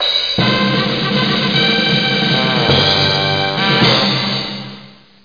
00729_Sound_fanfare.mp3